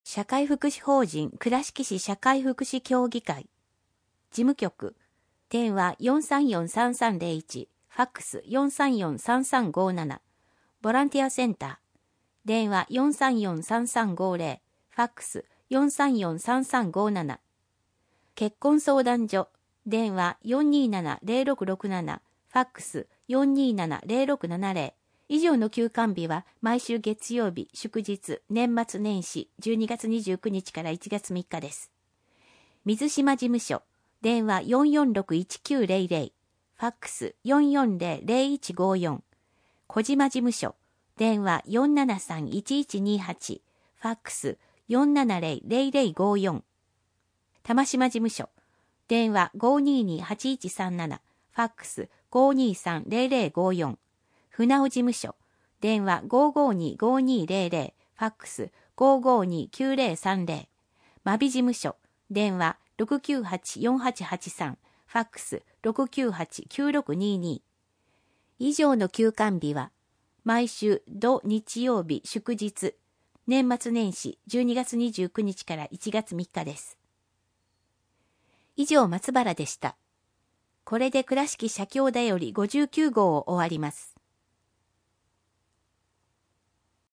社協だより第５９号 音訳版